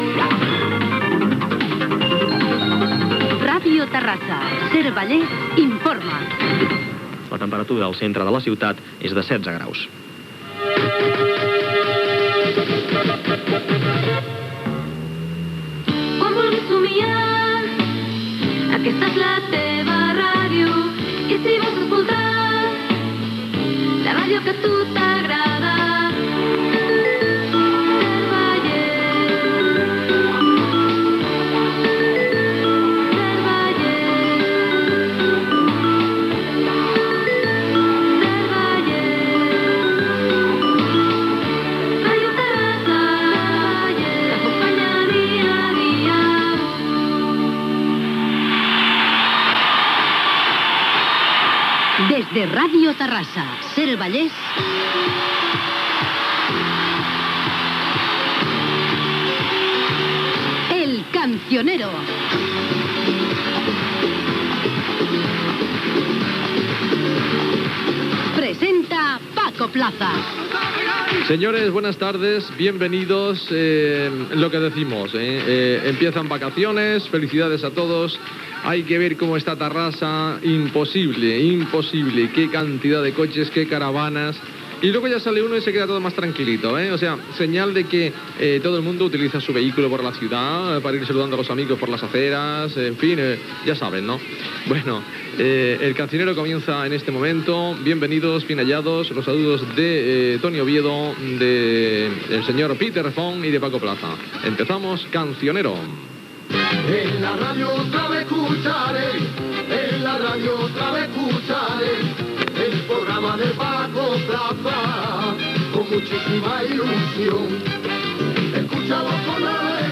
Temperatura, indicatiu cantat de l'emissora, careta del programa, equip, indicatiu cantat del programa, peticions musicals
Musical